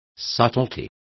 Complete with pronunciation of the translation of subtleties.